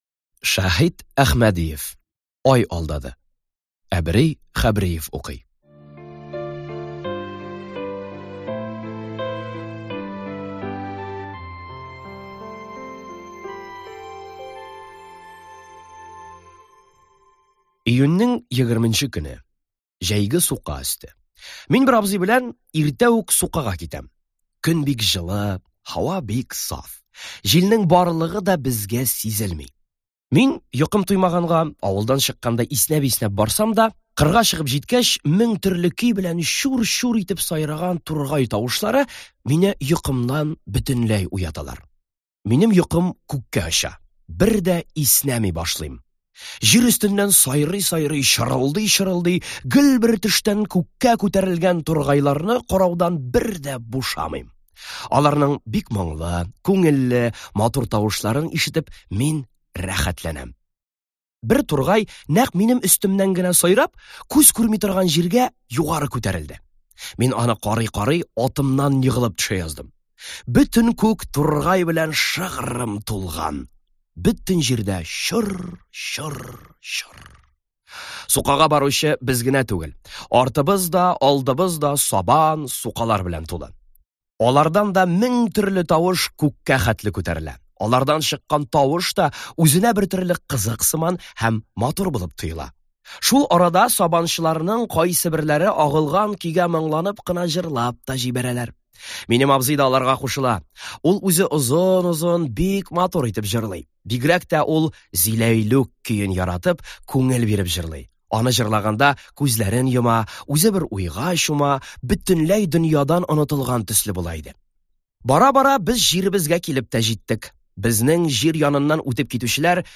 Аудиокнига Хикәяләр | Библиотека аудиокниг